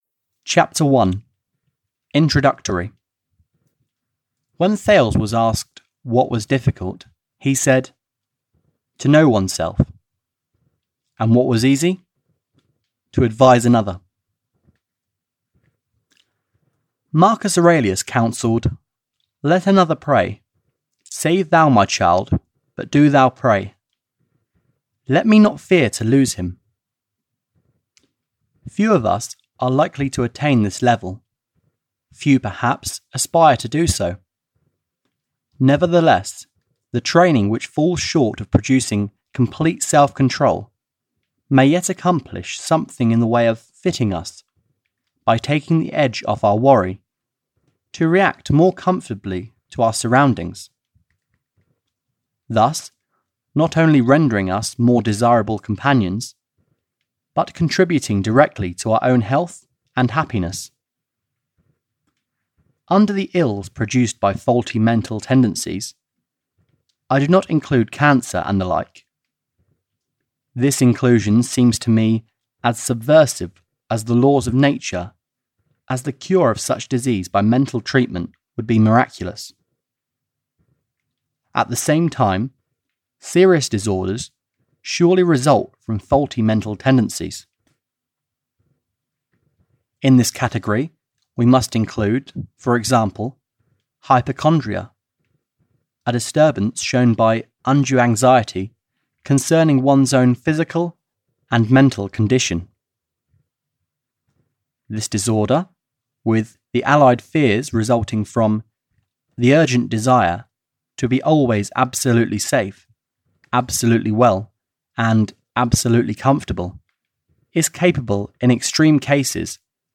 Why Worry (EN) audiokniha
Ukázka z knihy